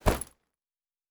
Bag 07.wav